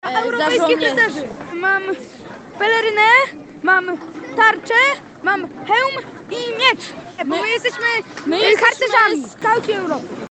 Po raz 13. przez Wrocław przeszedł Orszak Trzech Króli.
W orszaku spotkaliśmy niezwykle energicznych rycerzy.